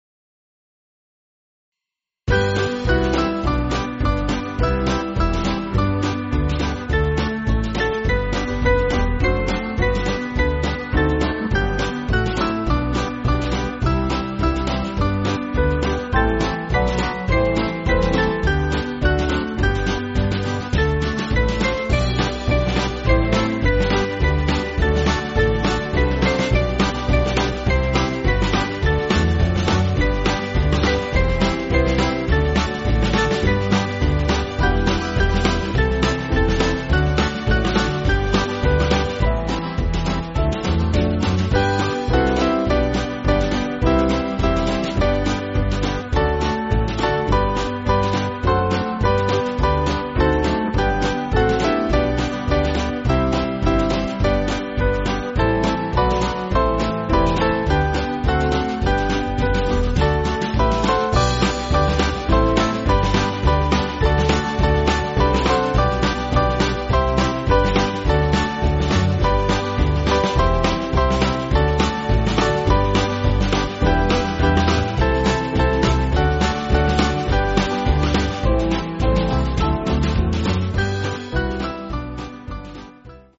Small Band
(CM)   4/G